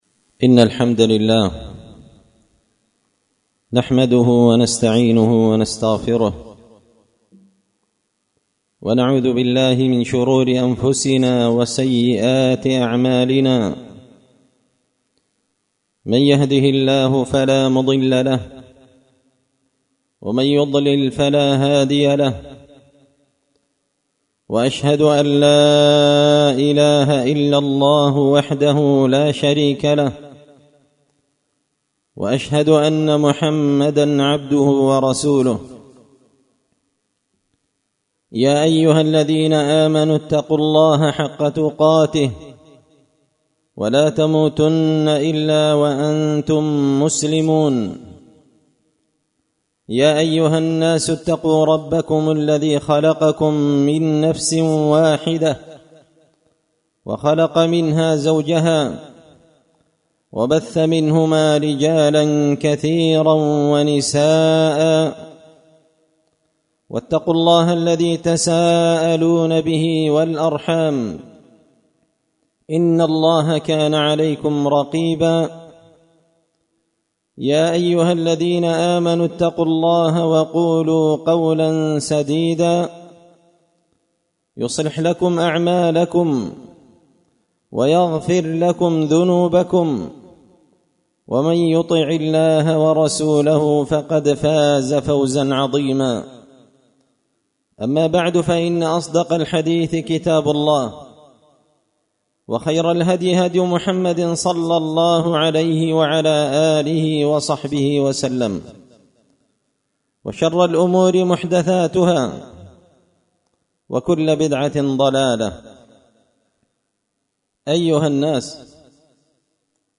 خطبة جمعة بعنوان -ظهر الفساد في البر والبحر بما كسبت أيدي الناس
دار الحديث بمسجد الفرقان ـ قشن ـ المهرة ـ اليمن